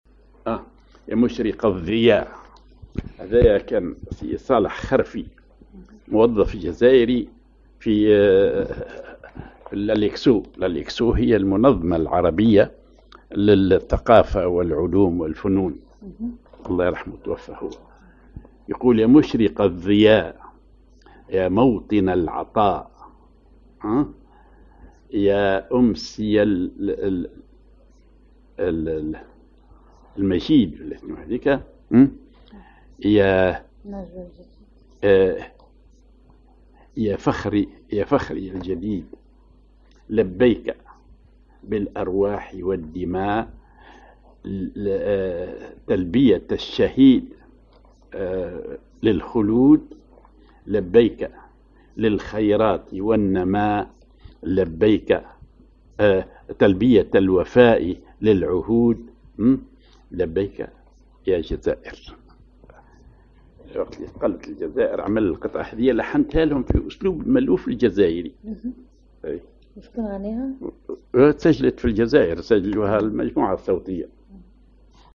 ar زيدان (حجاز أو أصبعين)
أغنية